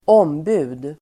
Uttal: [²'åm:bu:d]